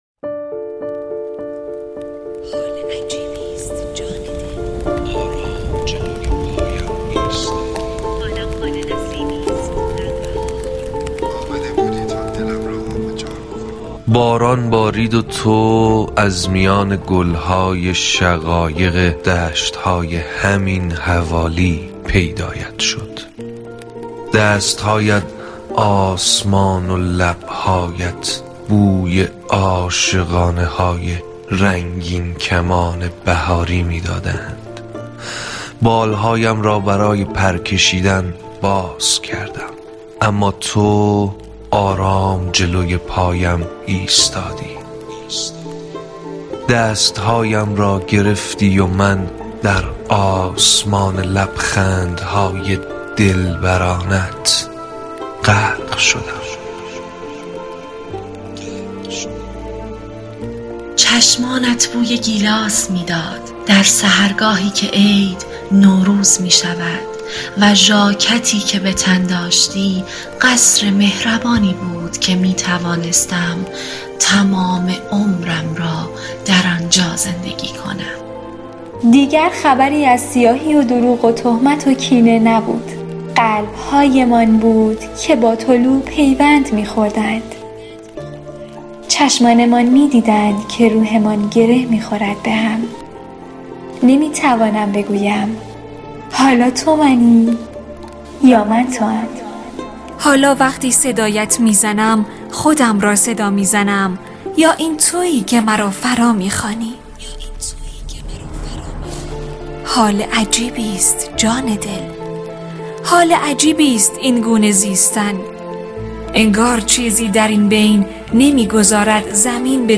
دکلمه گرم مثل آبی (نوروز99)